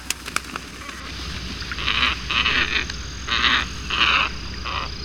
Ibis Lustroso,
Glossy Ibis
Plegadis falcinellus